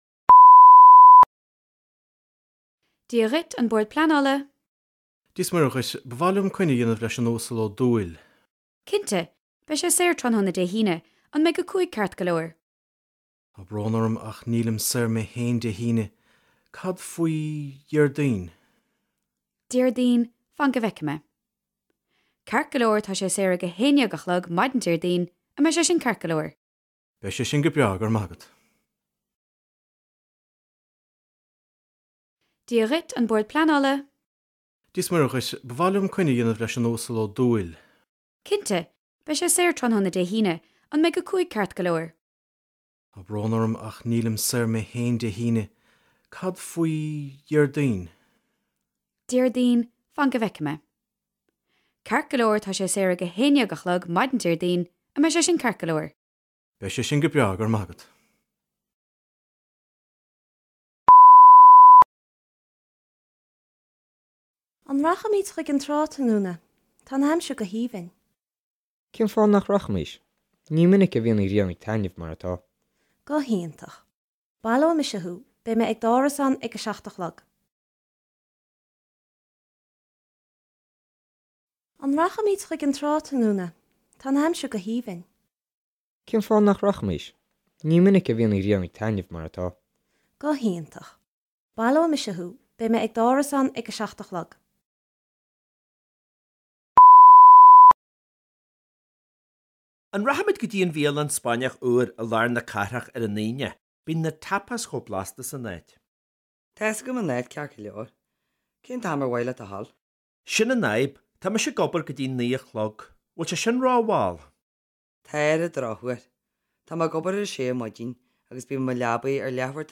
Coinní agus Socruithe: Cairde ag déanamh socruithe
Scil: Cluas
Cleachtadh cluastuisceana ina mbeidh an foghlaimeoir ag éisteacht le daoine ag caint faoina gcumas i dteangacha eile agus na dúshláin a bhaineann le foghlaim teanga nua.